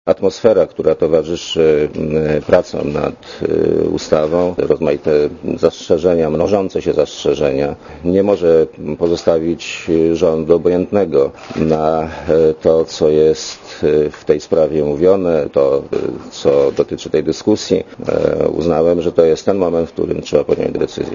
Mówi premier Leszek Miller (84kb)